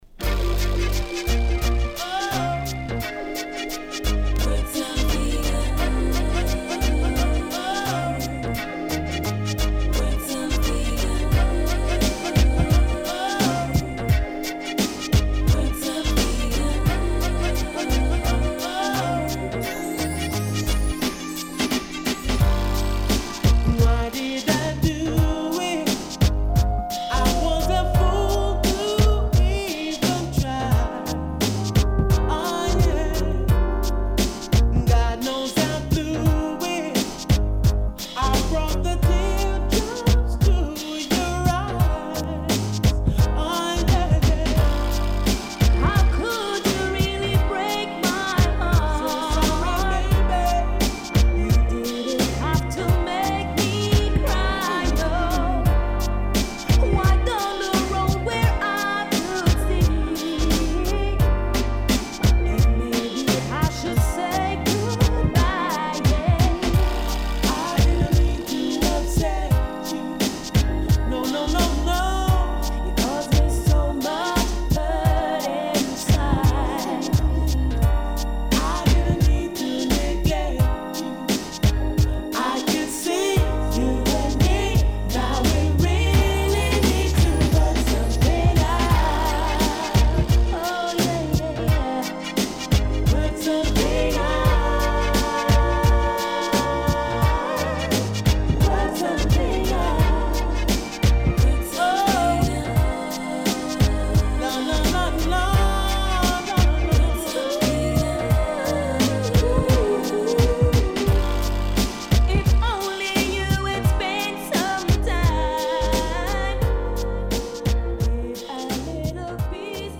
【12inch】
SIDE A:少しチリノイズ入ります。